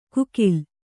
♪ kukil